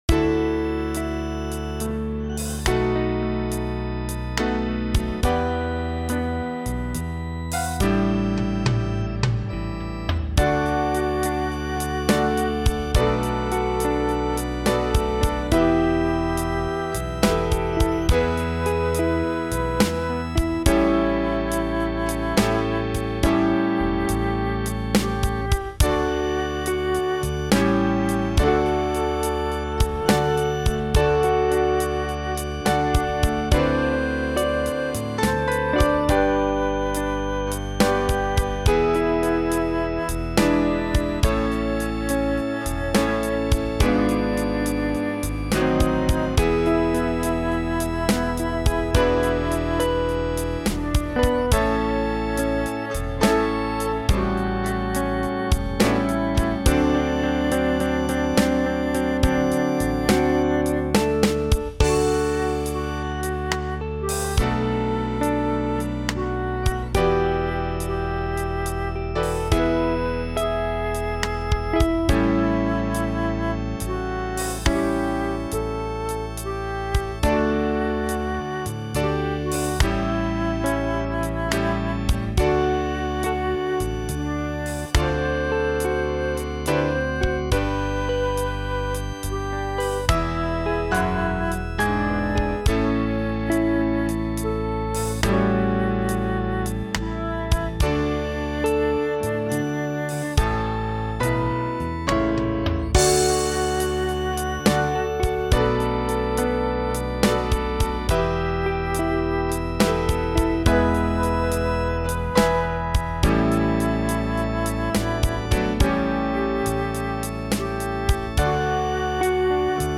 Eucharist song